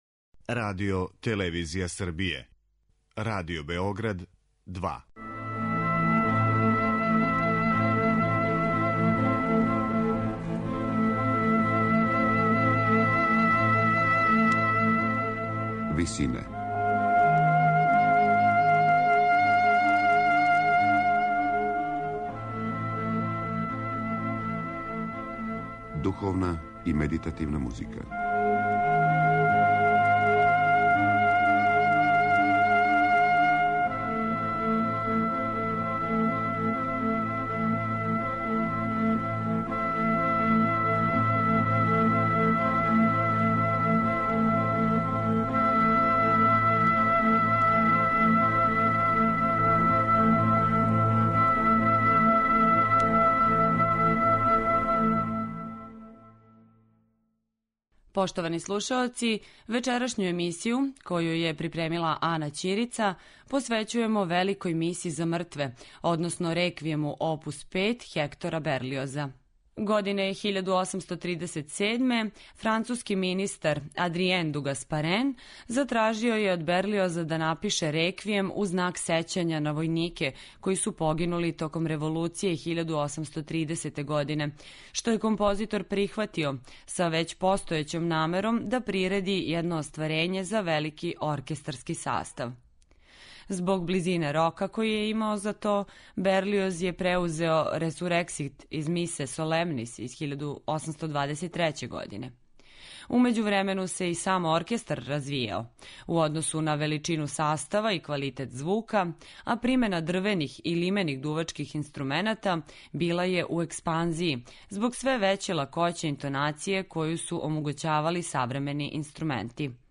у ВИСИНАМА представљамо медитативне и духовне композиције аутора свих конфесија и епоха.